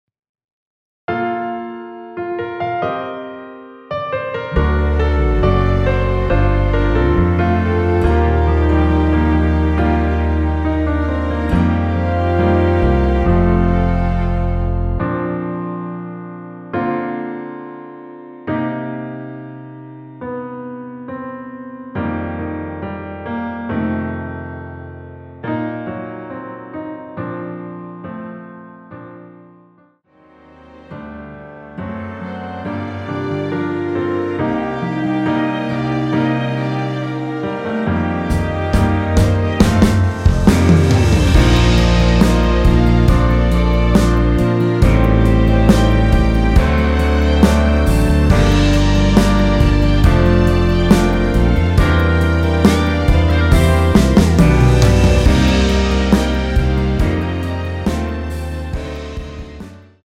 원키에서(-2)내린(1절+후렴)으로 진행되는 MR입니다.(본문의 가사와 미리듣기 확인)
Bb
앞부분30초, 뒷부분30초씩 편집해서 올려 드리고 있습니다.
중간에 음이 끈어지고 다시 나오는 이유는